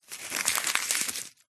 Звуки билета
Звук банкноты nЗвучание билета nАудио купюры nШум проходного билета